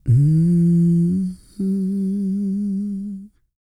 E-CROON P303.wav